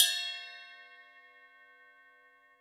Cymbol Shard 02.wav